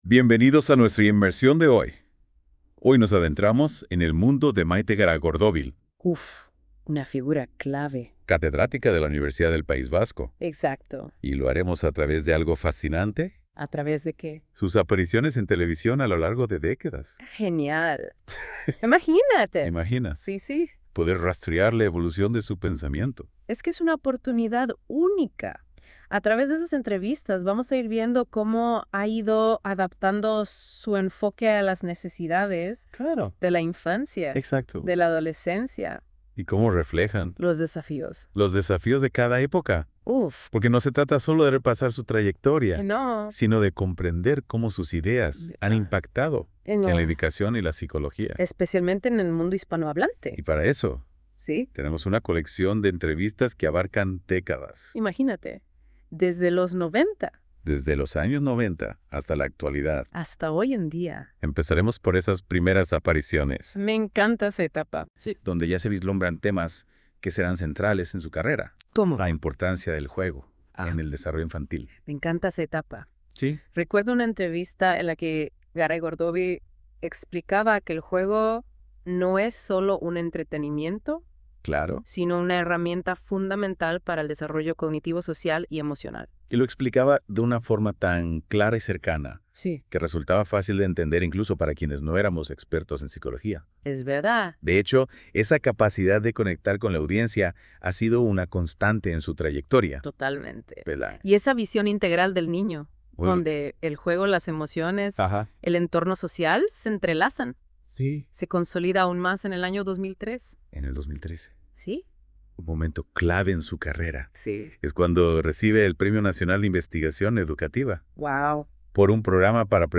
Entrevistas en television
Reportaje-Entrevista TV Euskal Telebista (ETB). 4-1-1996. Los juguetes infantiles.